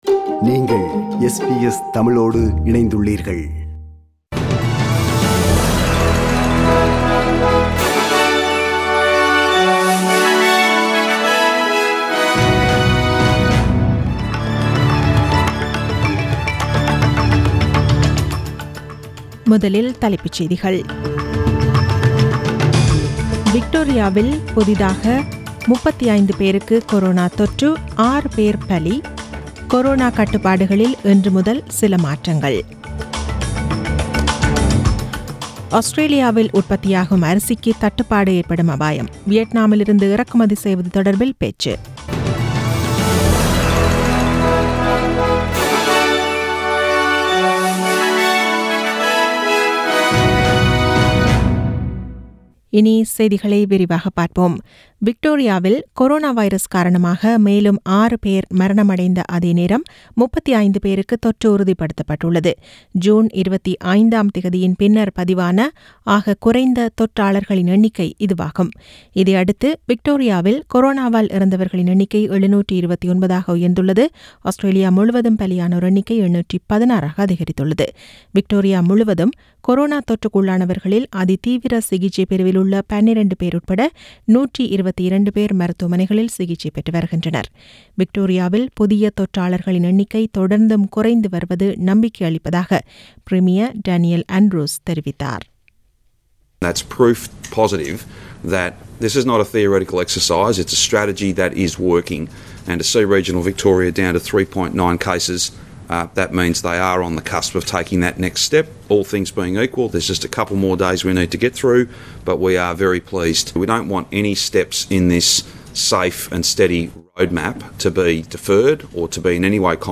The news bulletin was aired on 14 September 2020 (Monday) at 8pm.